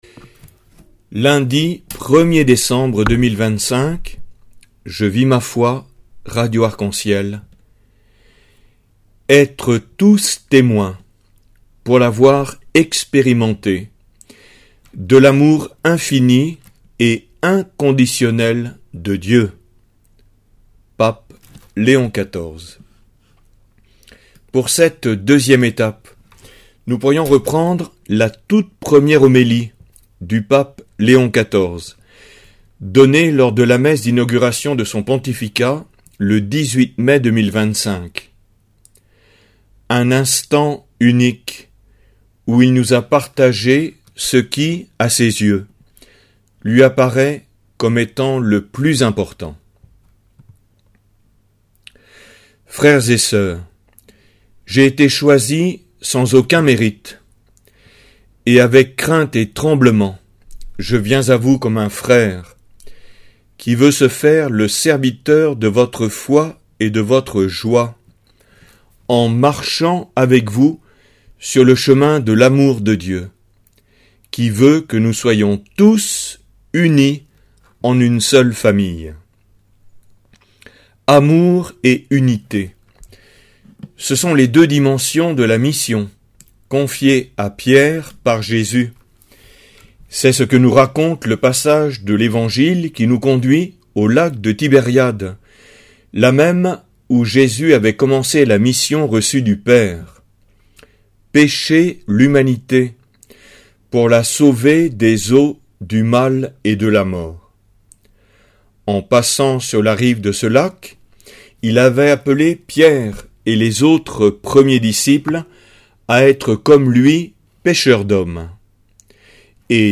Prédication disponible en format audio.